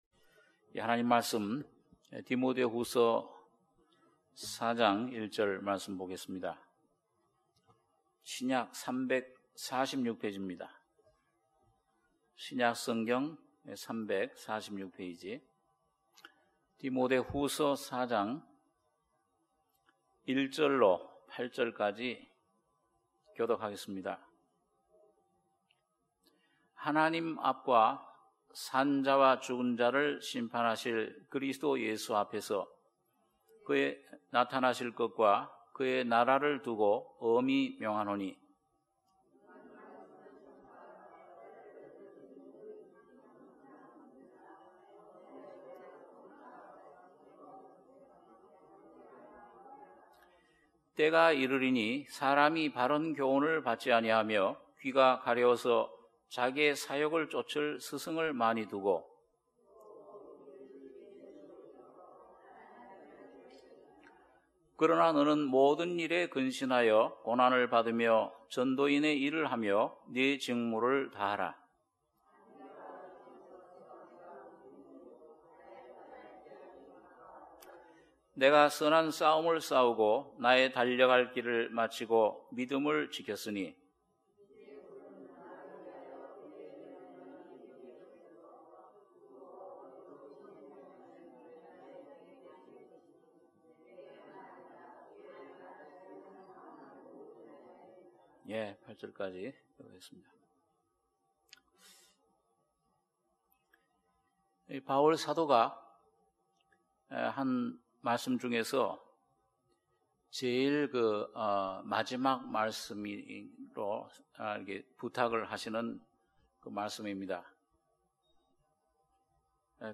주일예배 - 디모데후서 4장 2절-8절(오후말씀)